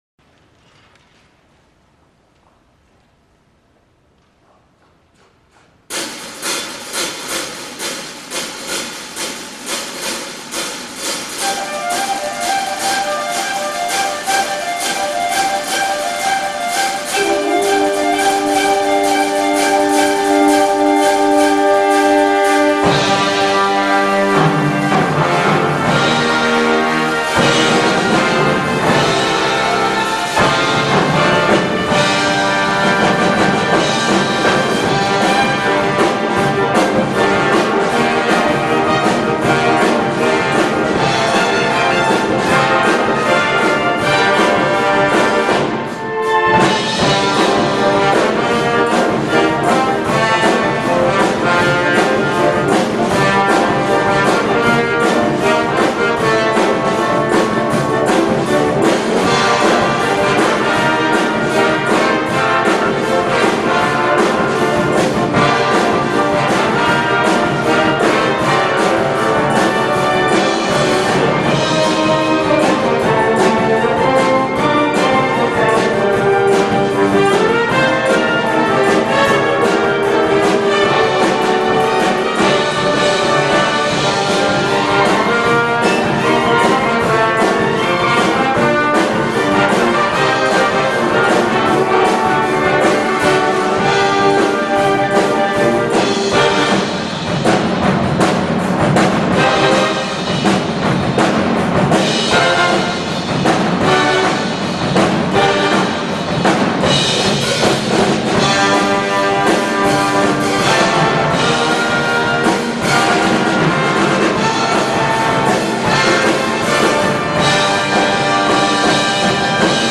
ラストクリスマスコンサートにて